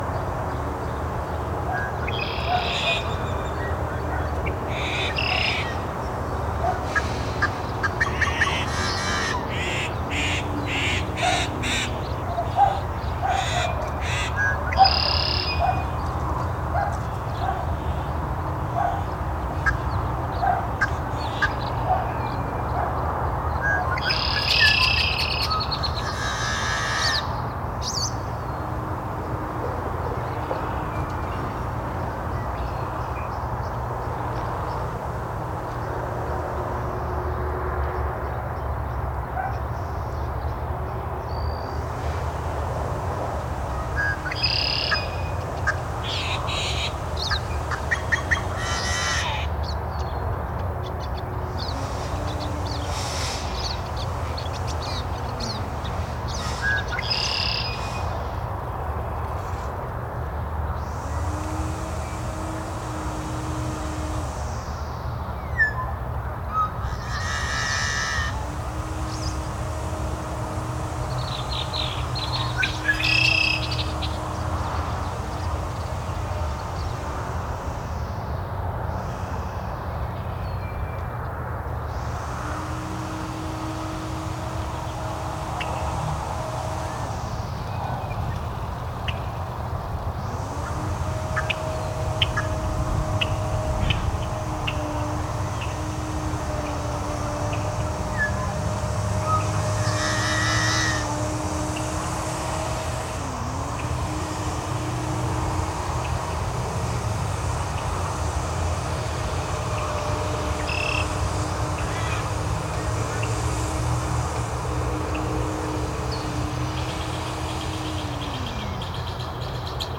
Chestermere has a sound, waves reverberate through our neighbourhoods and give texture to our lives.